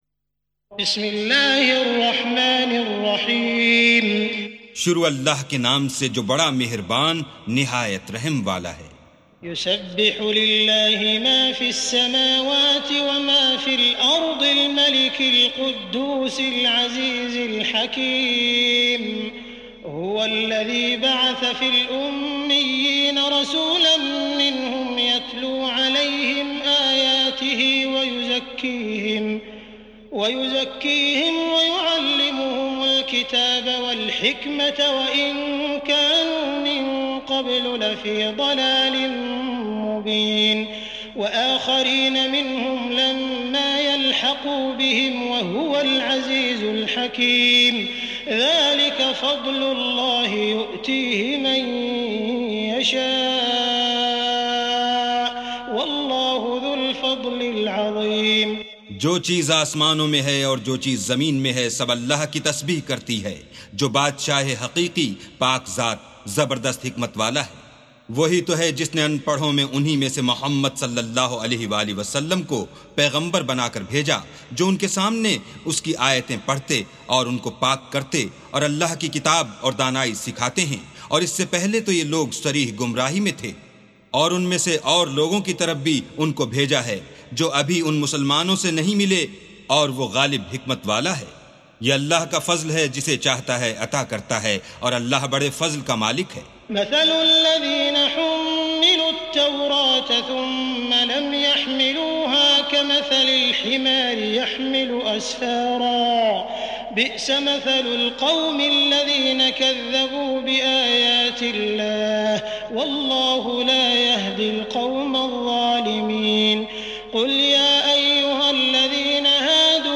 سُورَةُ الجُمُعَةِ بصوت الشيخ السديس والشريم مترجم إلى الاردو